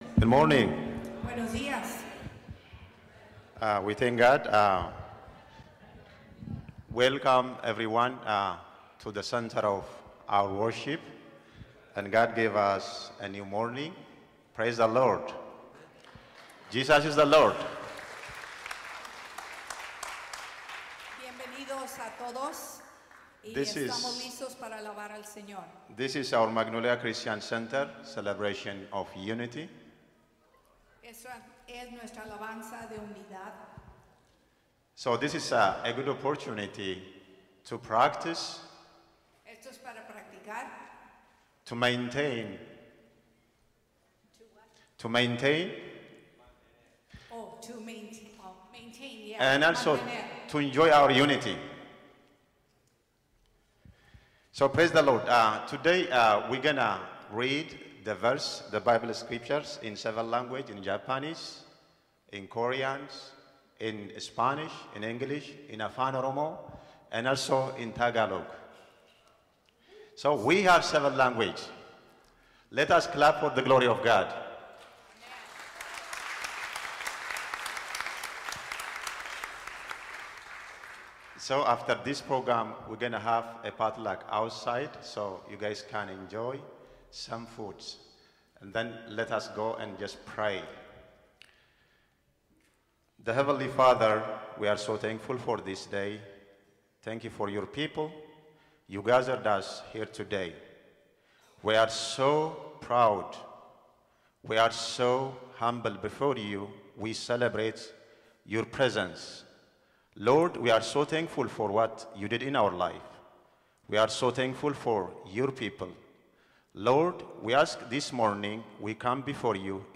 All the churches that meet on our campus gathered together for one service followed by a pot luck.
It was a glorious time experiencing worship together and hearing each others language.